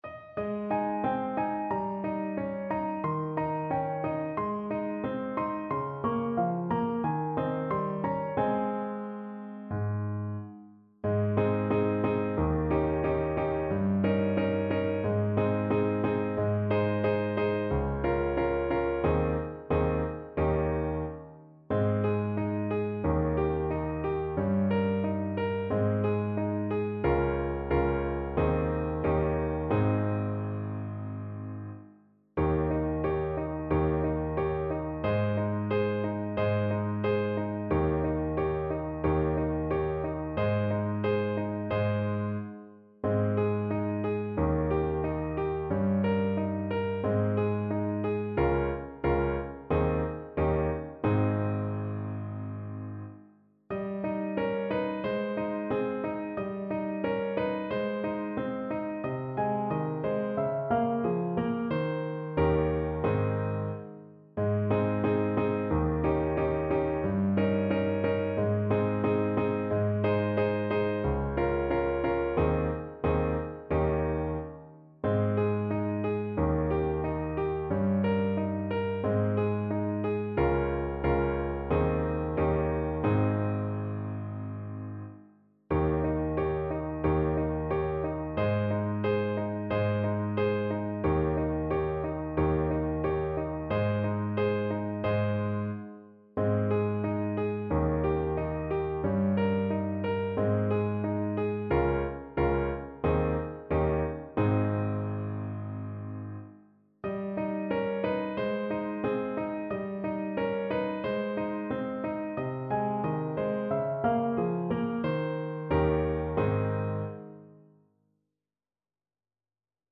4/4 (View more 4/4 Music)
Eb4-Eb5
Andante, con espressione =90